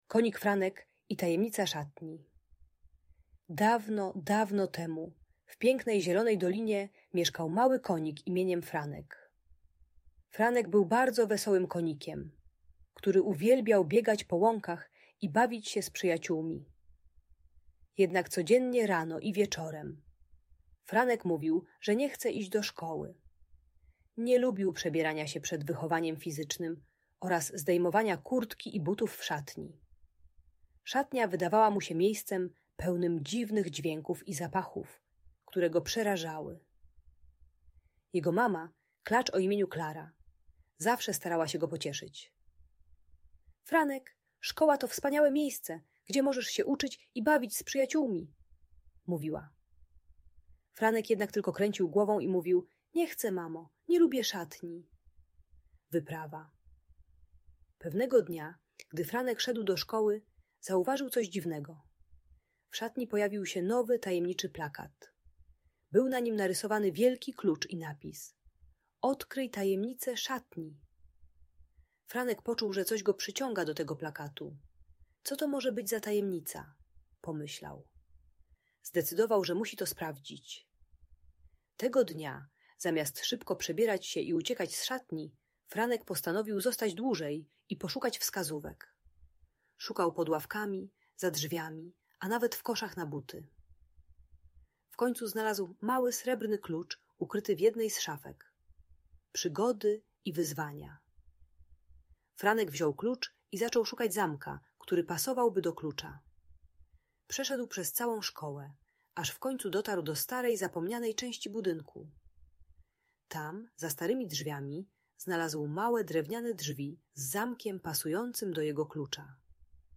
Przygody Franka: Tajemnicza Szatnia - Bajka dla Dzieci - Audiobajka